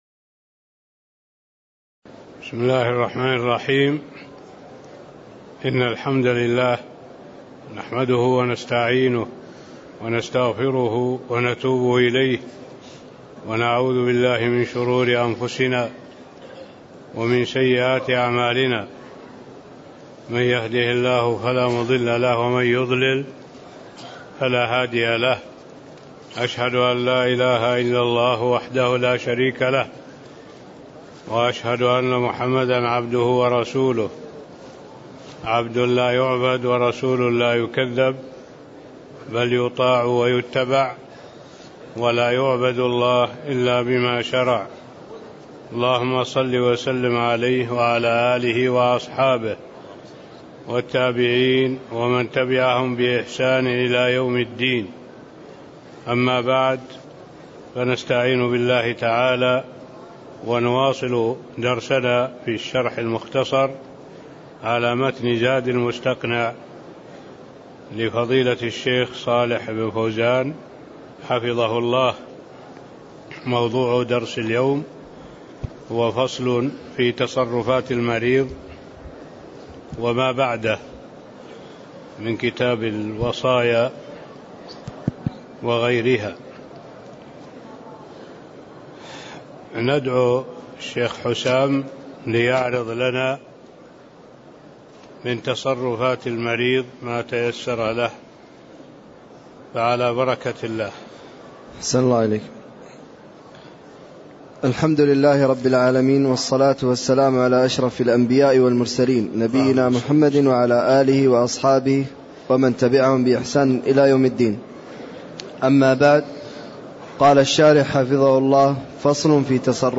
تاريخ النشر ١٥ جمادى الأولى ١٤٣٥ هـ المكان: المسجد النبوي الشيخ